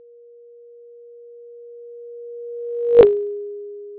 Let a sound source produce a single tone at frequency F0.
Velocity of object: 25m/s
Sound generated by source: 440Hz tone
Perceived Frequency at the closest point: 440 Hz